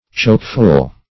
Choke-full \Choke"-full`\, a.